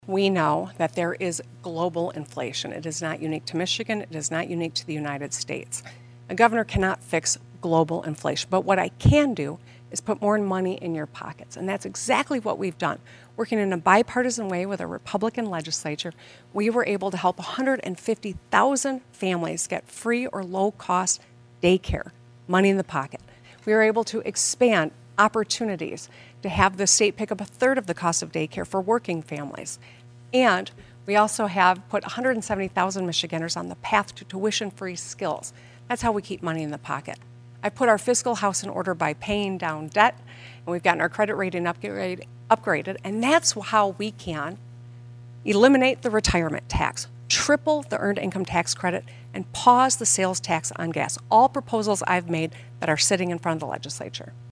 The debate brought the two contenders together for hour-long event at Oakland University.